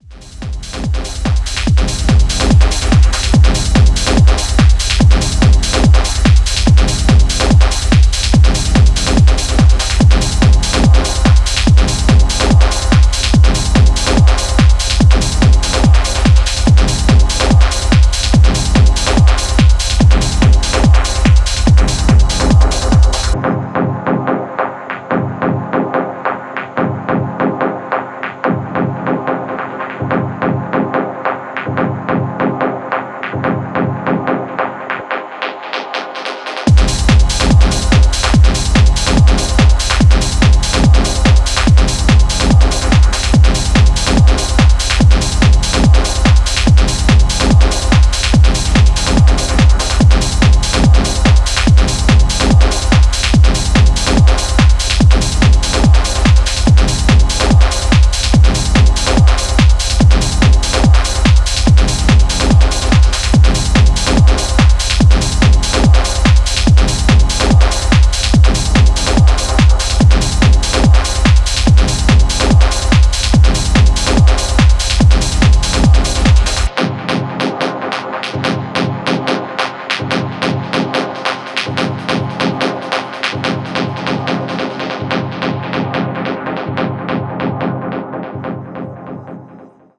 Styl: Techno